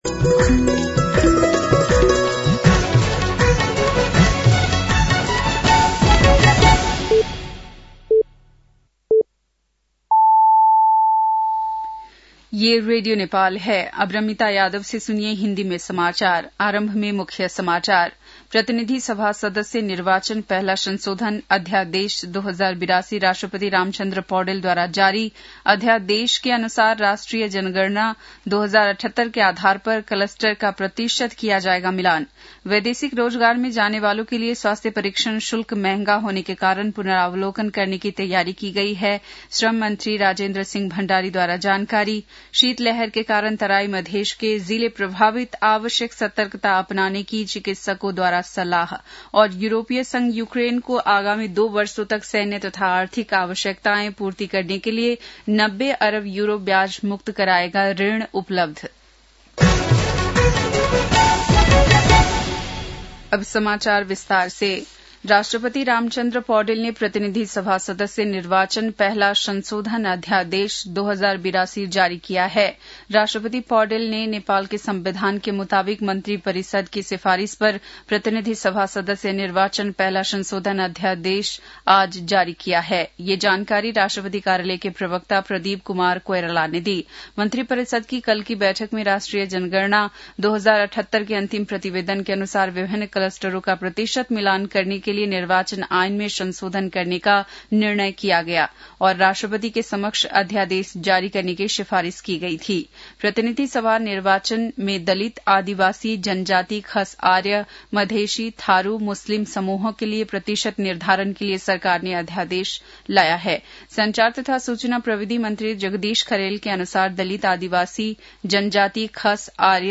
बेलुकी १० बजेको हिन्दी समाचार : ४ पुष , २०८२
10-PM-hindi-NEWS-9-04.mp3